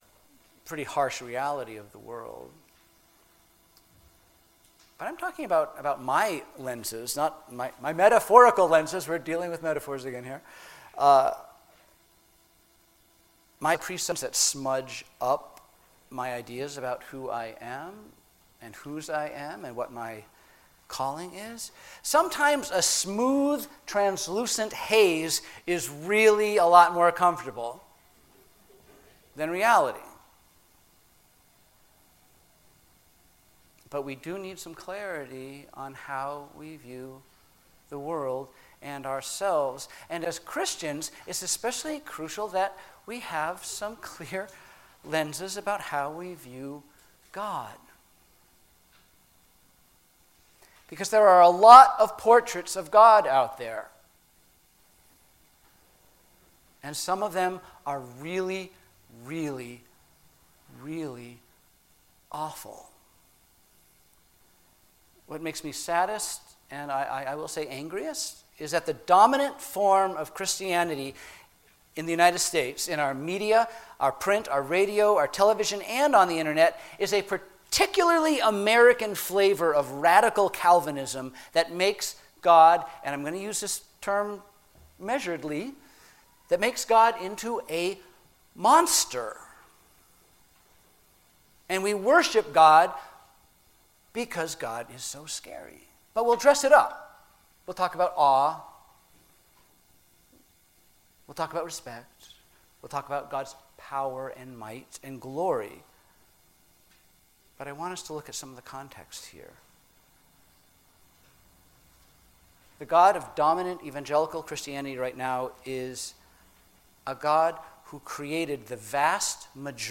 Sermons What Is God Like?
what-is-god-like-sermon-series-intro.mp3